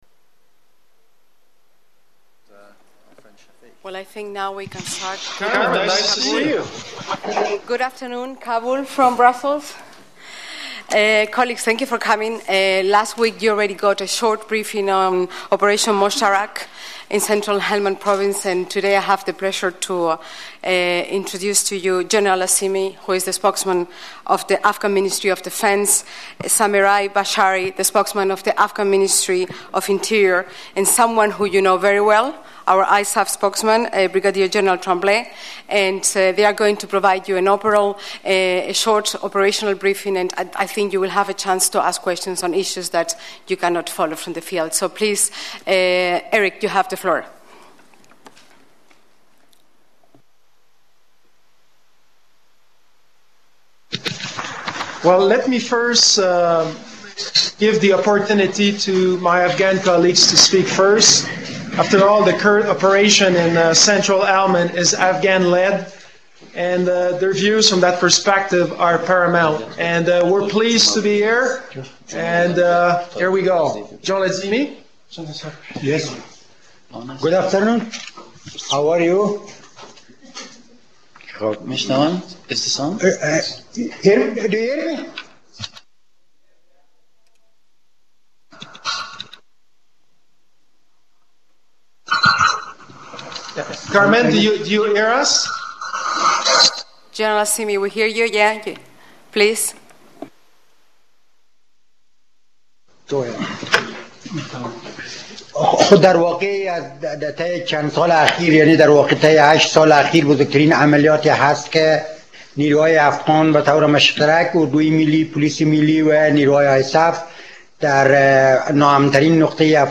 Press briefing on Operation Moshtarak with Brigadier-General Tremblay, ISAF Spokesperson, General Azimi, Spokesperson for the Afghan Ministry of Defence and Zemerai Bashary, Spokesperson for the Afghan Ministry of Interior